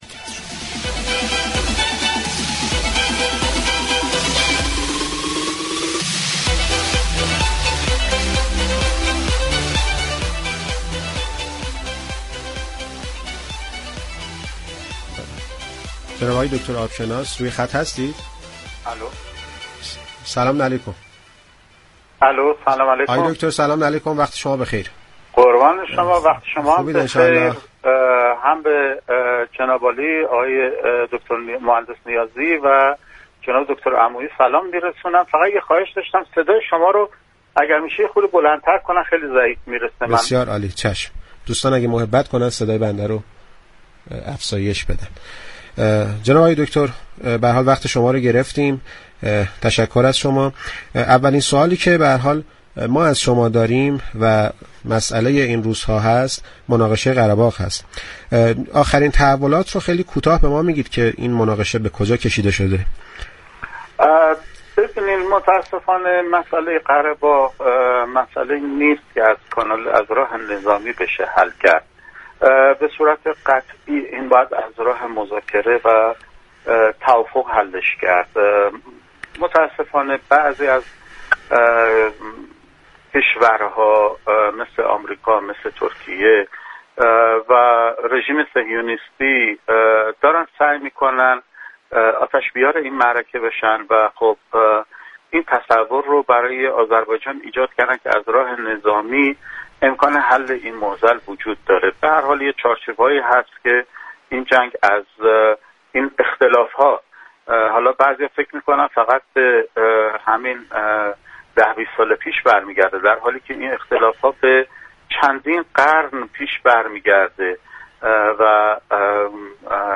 درگفتگو با برنامه سعادت آباد